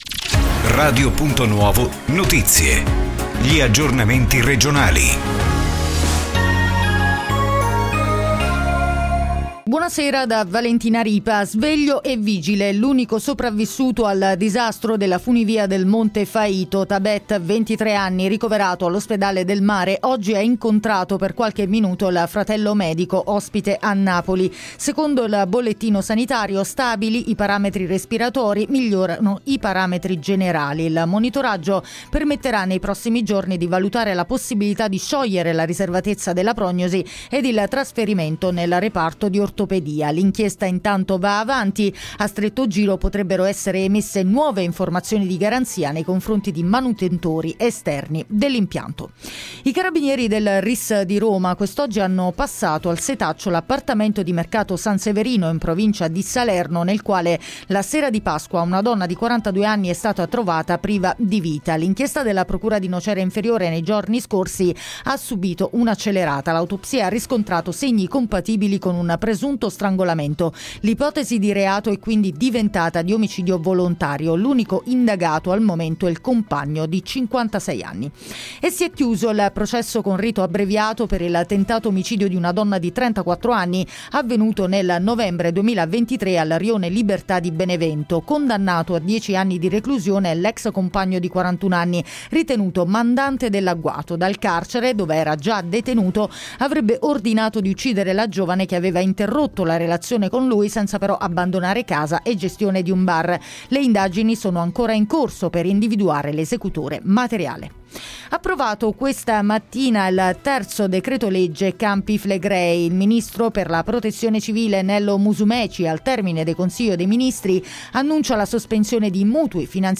Ultimo Notiziario